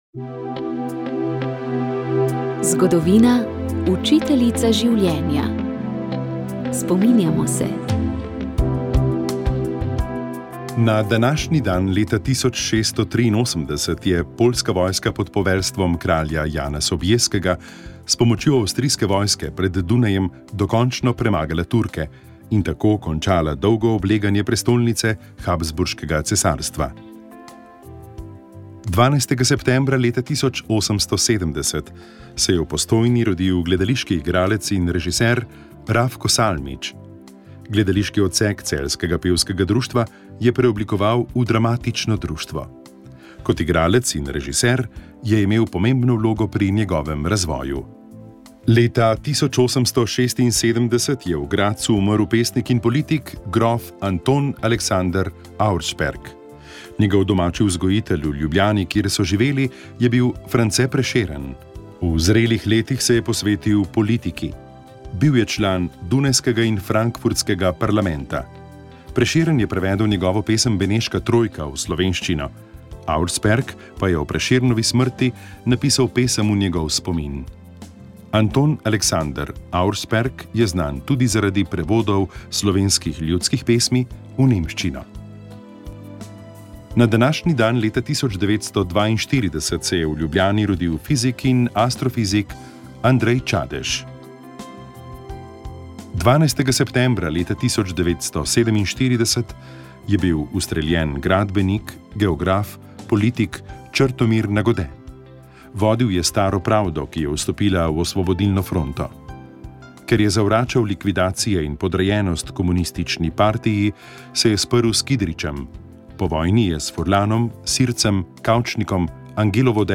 Sv. maša iz stolne cerkve sv. Janeza Krstnika v Mariboru 9. 9.